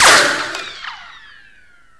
WpnVauseHitRicB.wav